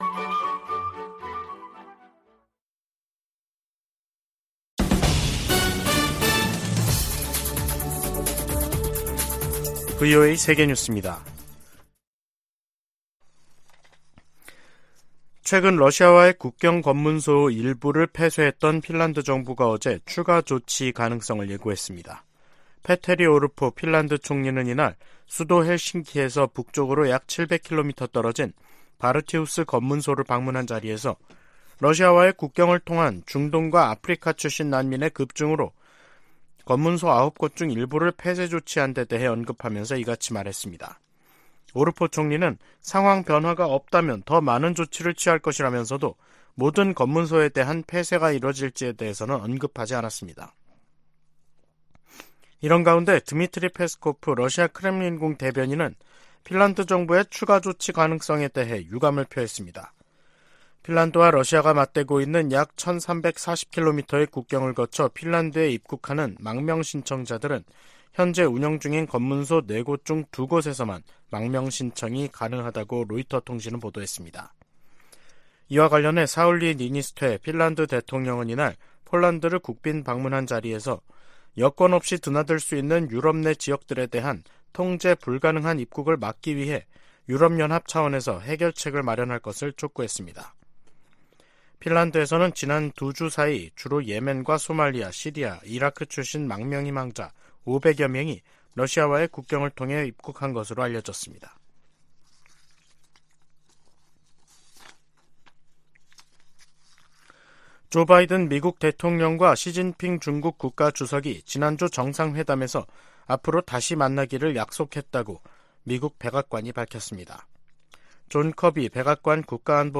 VOA 한국어 간판 뉴스 프로그램 '뉴스 투데이', 2023년 11월 21일 2부 방송입니다. 국제해사기구(IMO)는 북한이 오는 22일부터 내달 1일 사이 인공위성 발사 계획을 통보했다고 확인했습니다. 북한의 군사정찰위성 발사 계획에 대해 미 국무부는 러시아의 기술이 이전될 가능성을 지적했습니다. 한국 정부가 남북 군사합의 효력 정지를 시사하고 있는 가운데 미국 전문가들은 합의 폐기보다는 중단했던 훈련과 정찰 활동을 재개하는 편이 낫다고 진단했습니다.